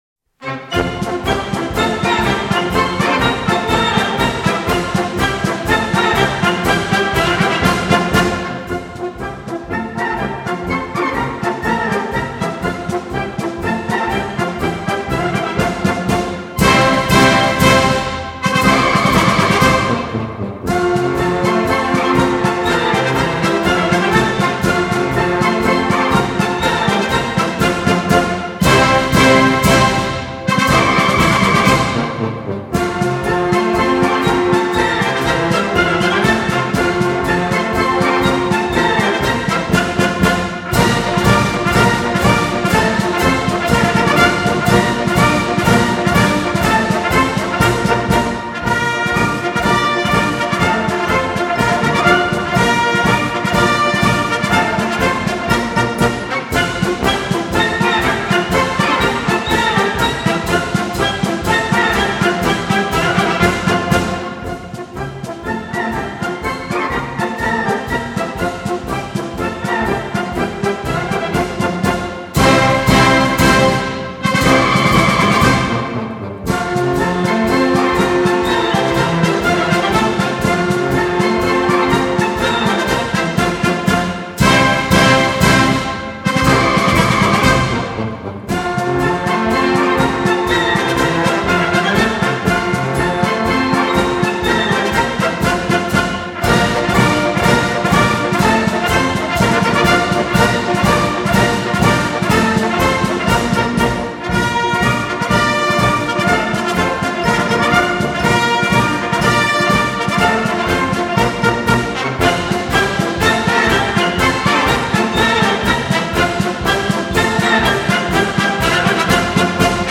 Просьба опознать марши
В "Золотом теленке" звучит марш егерей Суворова